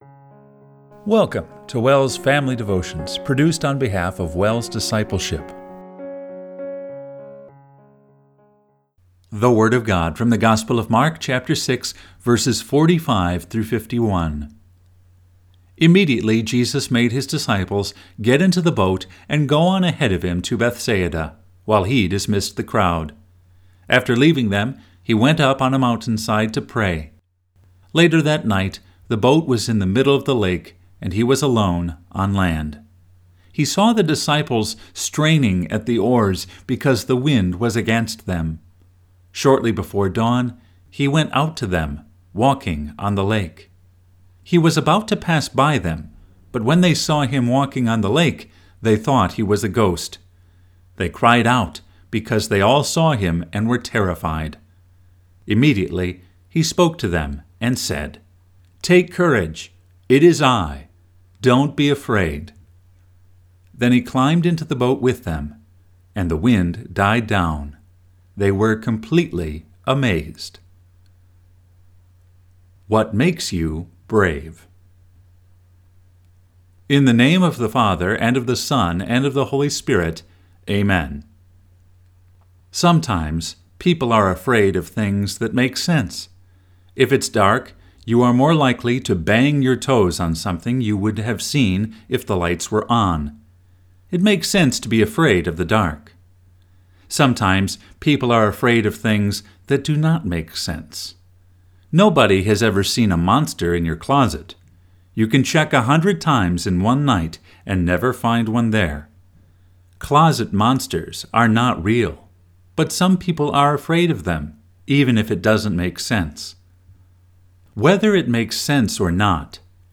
Family Devotion – August 9, 2024